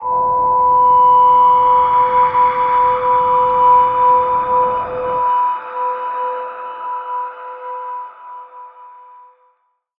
G_Crystal-B5-pp.wav